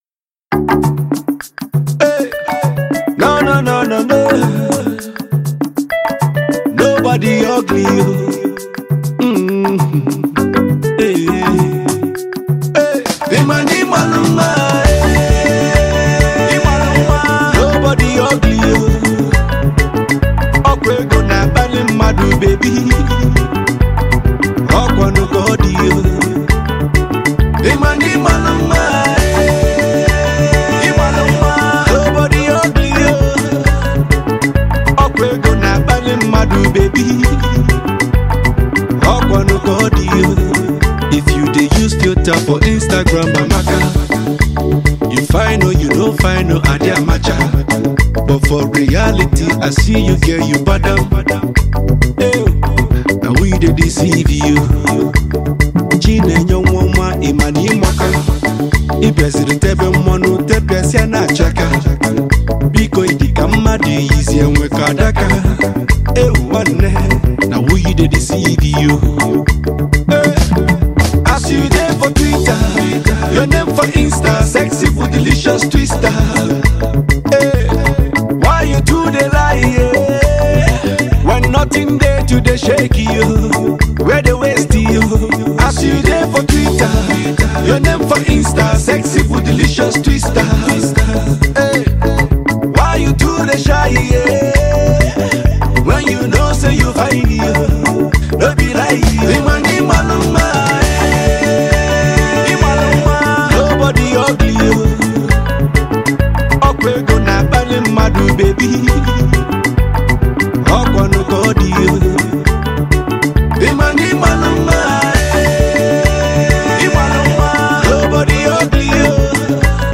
Naija Music